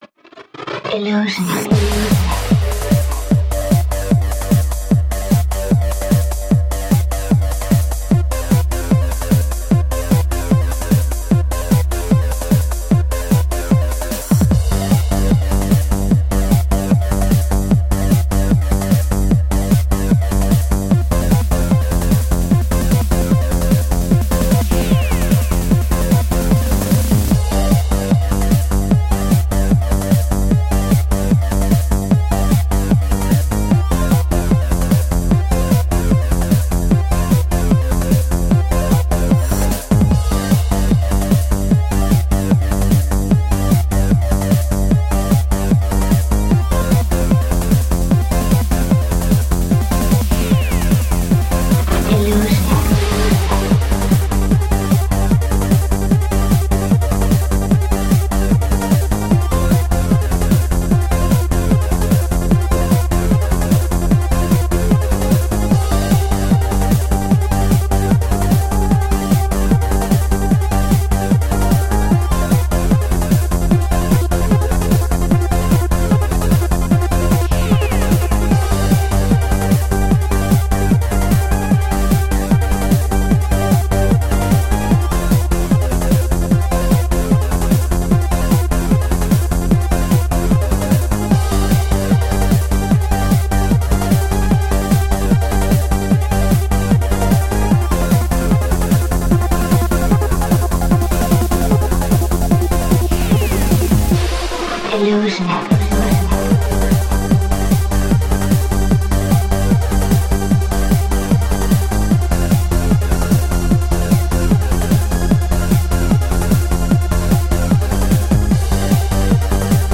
Happy trance.